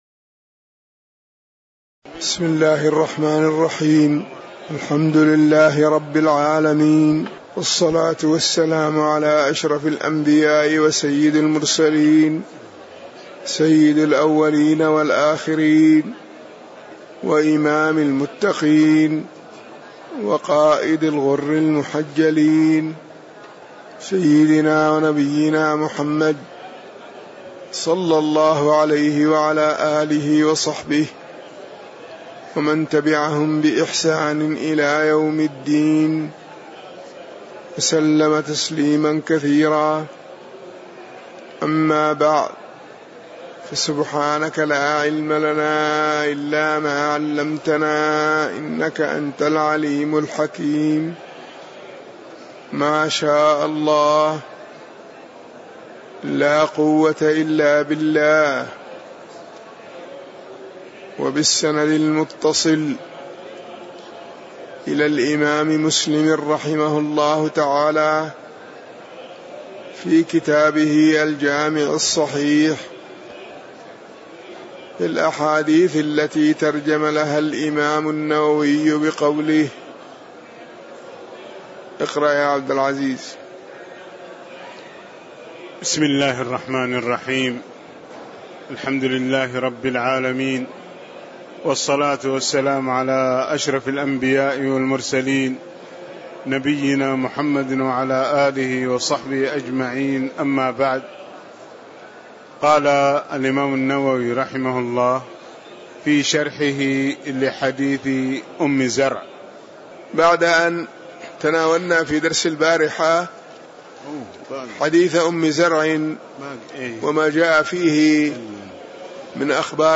تاريخ النشر ٣ رمضان ١٤٣٧ هـ المكان: المسجد النبوي الشيخ